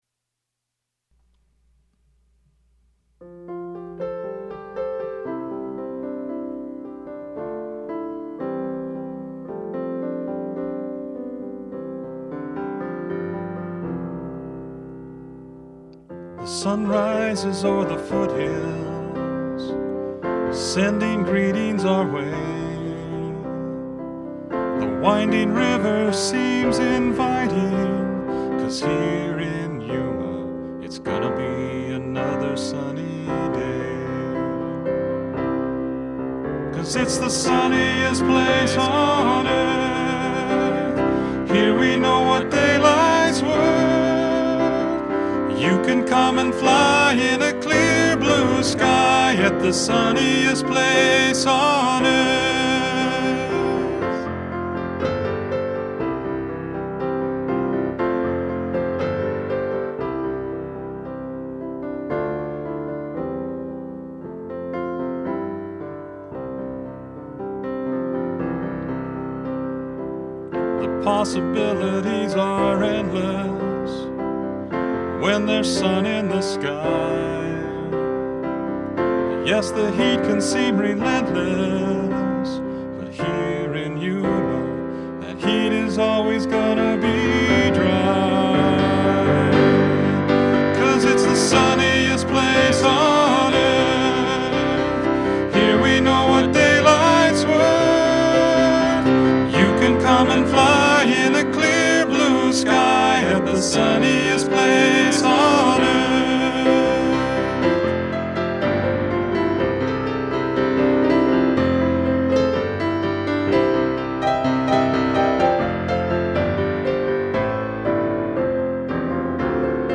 I thought there should be a song to observe this appealing quality. This is a fun song to play and improvise with.
The file below was the first recording that I did with my meager limited home equipment, but it includes some great harmony that I thought was worth including. I realize the quality may not be entirely adequate (this is not to say that with the best equipment my voice could be more than adequate), but I wanted to include it nonetheless.